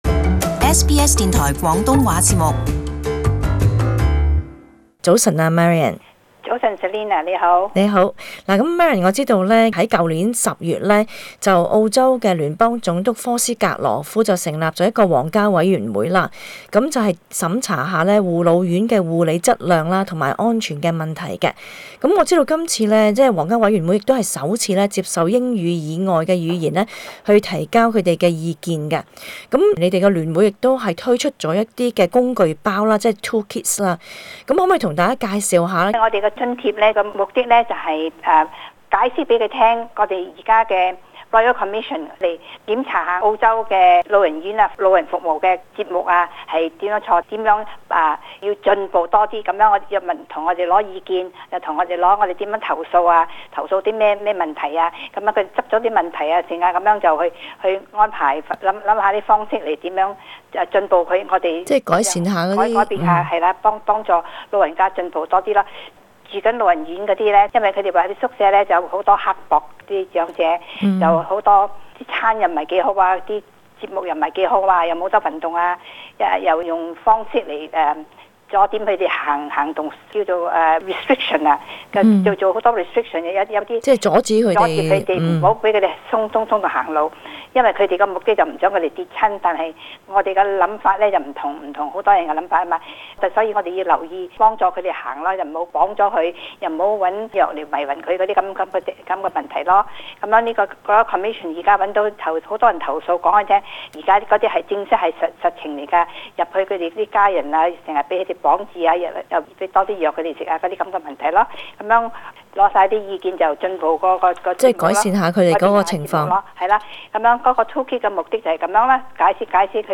【社區專訪】調查護老院質量皇家委員會首次接受非英語意見書